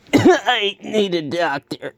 Category 🗣 Voices
cough funny funny-voice hurt injured male man pain sound effect free sound royalty free Voices